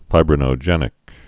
(fībrə-nō-jĕnĭk)